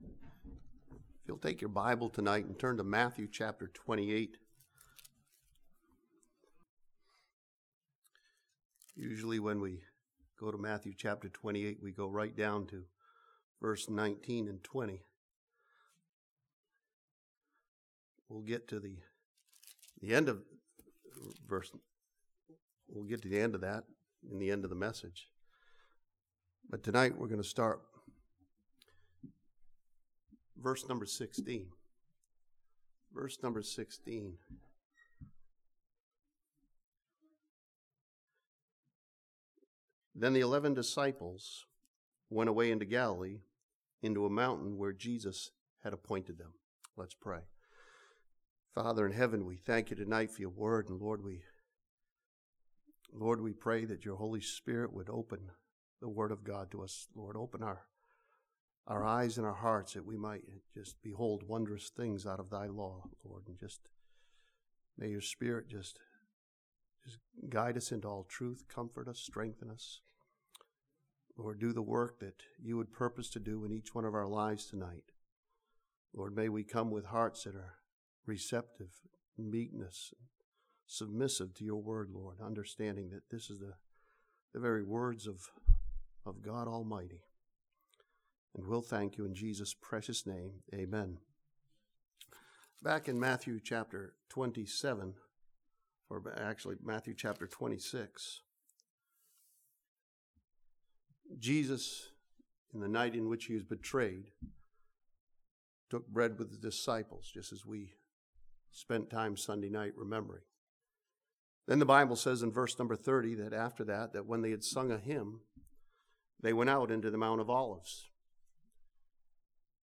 This sermon from Matthew chapter 28 encourages believers by studying the blessing of knowing His presence in our lives.